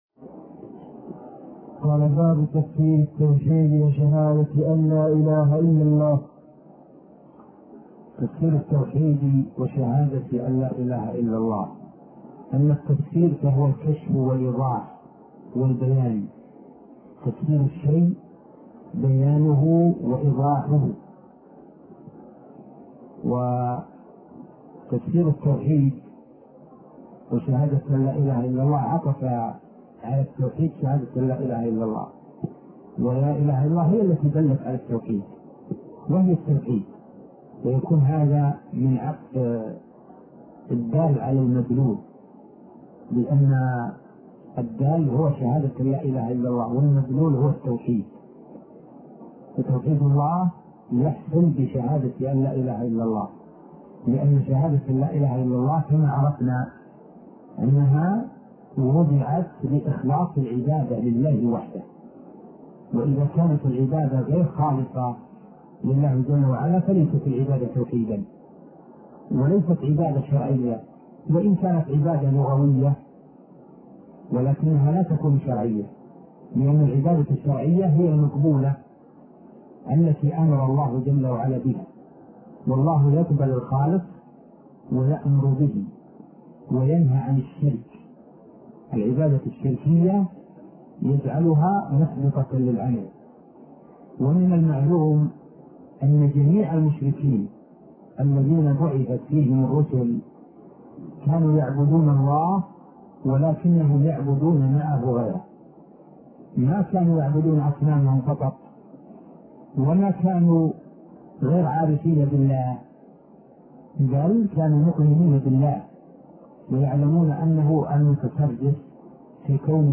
عنوان المادة الدرس ( 29) شرح فتح المجيد شرح كتاب التوحيد تاريخ التحميل الجمعة 16 ديسمبر 2022 مـ حجم المادة 29.47 ميجا بايت عدد الزيارات 233 زيارة عدد مرات الحفظ 122 مرة إستماع المادة حفظ المادة اضف تعليقك أرسل لصديق